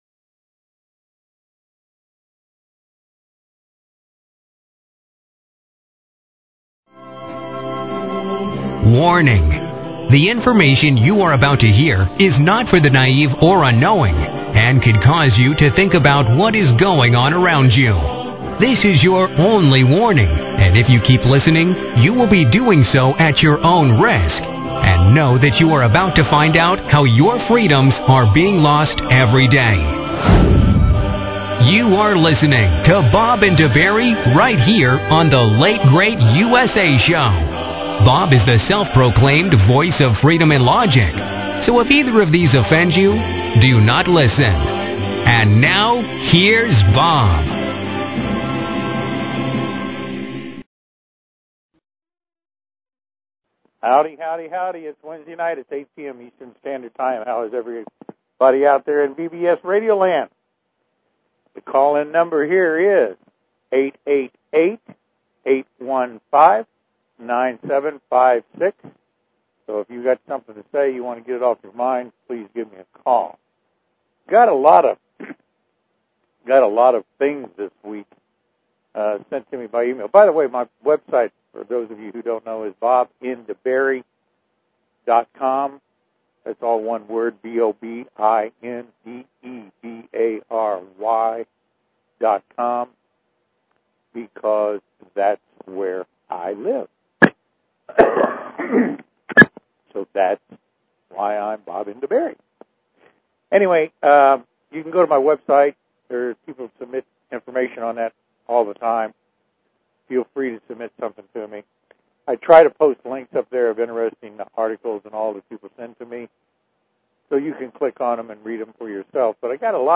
Talk Show Episode, Audio Podcast, The_Late_Great_USA and Courtesy of BBS Radio on , show guests , about , categorized as